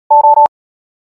warning_soft.wav